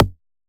edm-kick-57.wav